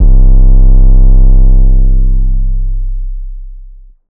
TS - 808 (4).wav